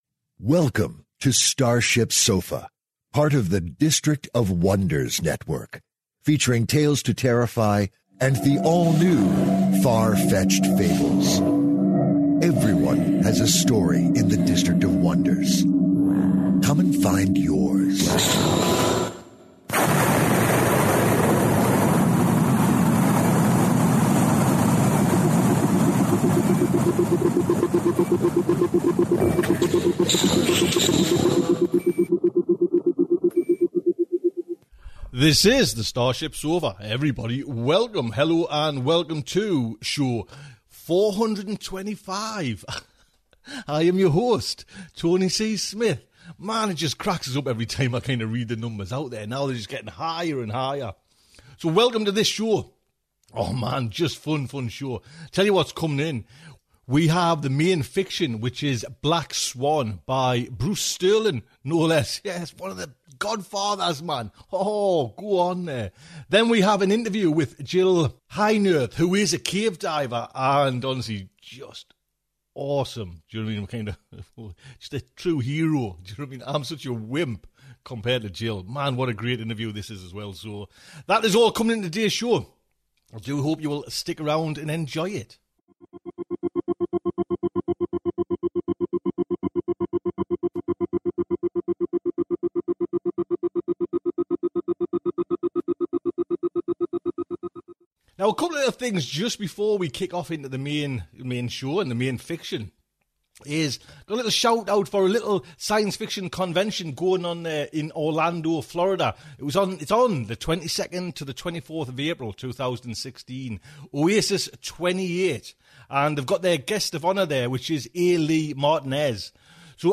“Black Swan” Espionage Amongst Cyber-Punk Travelers Audio Story